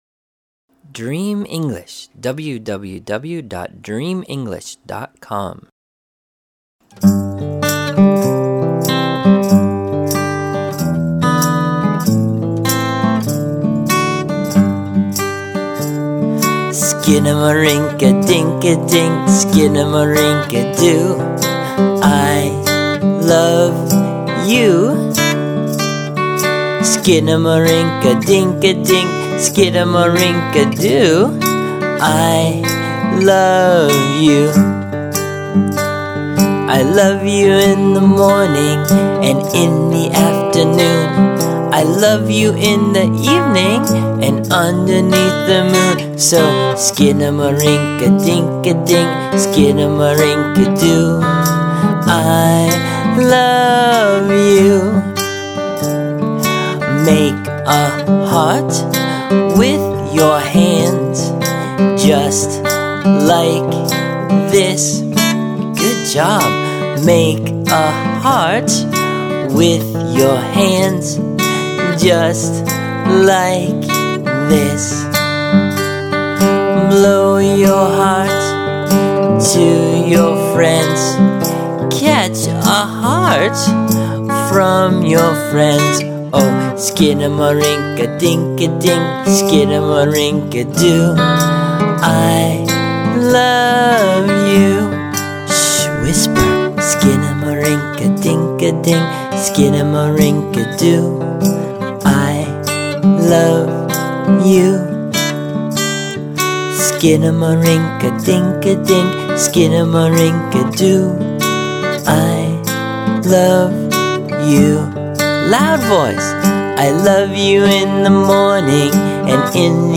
Traditional Song